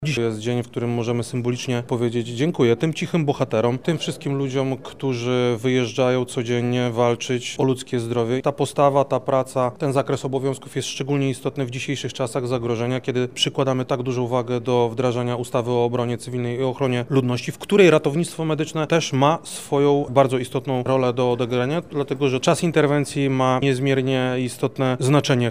Choć do święta jeszcze trochę, to uroczyste obchody w Centrum Powiadamiania Ratunkowego w Lublinie odbyły się już dzisiaj (10.10).
Od ich interwencji, ich doposażenia, od ich kompetencji, wiedzy, doświadczenia, refleksu zależy zdrowie i życie – mówi Krzysztof Komorski, Wojewoda Lubelski.
Krzysztof-Komorski-1.mp3